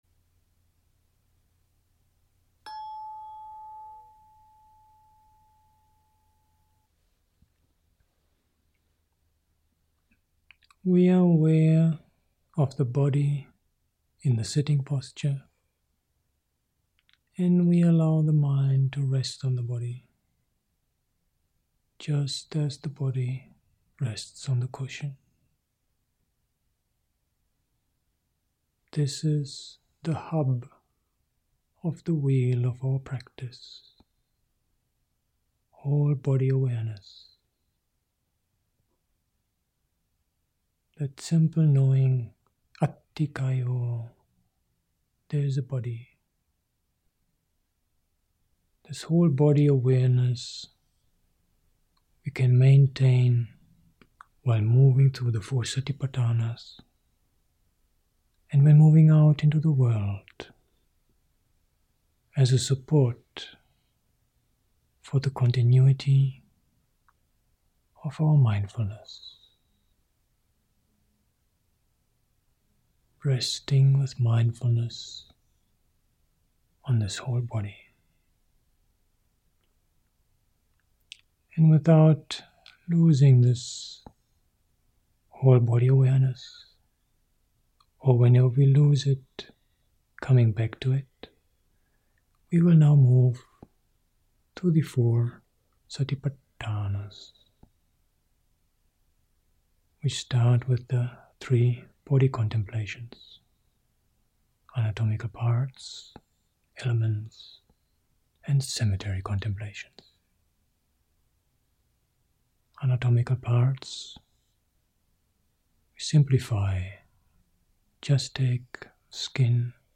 Guided meditation of Exploring Four Satipatthanas (40 min.): https